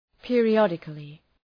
{,pıərı’ɒdıklı}